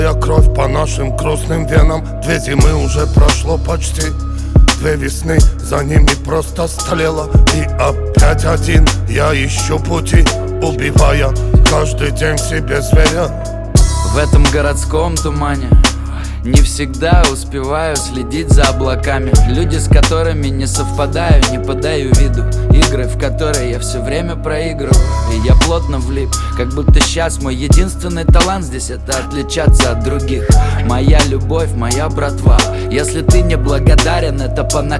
Жанр: Рэп и хип-хоп / Иностранный рэп и хип-хоп / Русские
# Hip-Hop